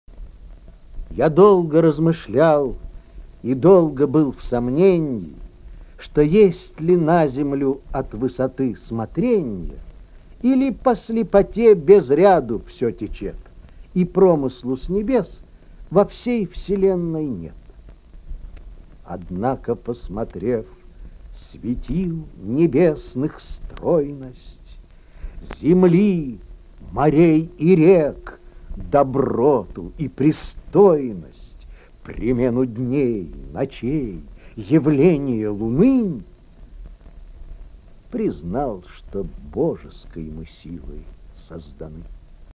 1. «Читает Я. Смоленский – Михаил Ломоносов – Я долго размышлял и долго был в сомненье» /